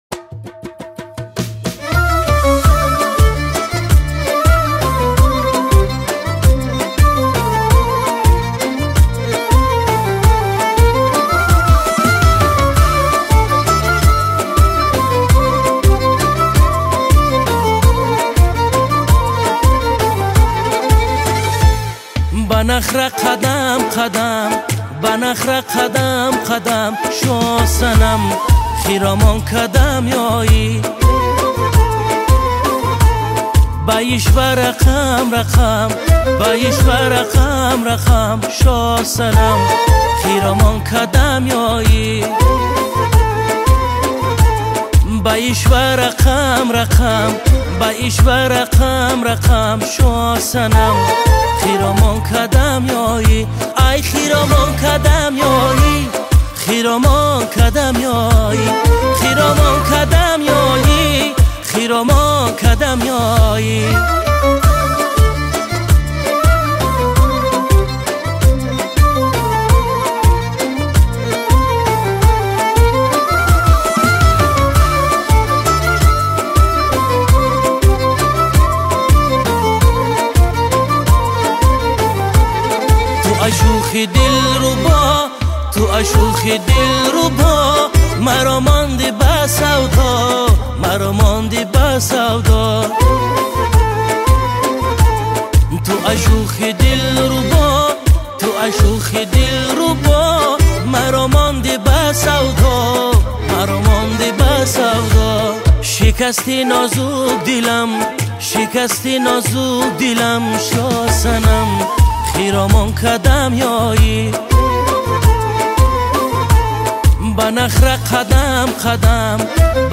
Категория: Узбекские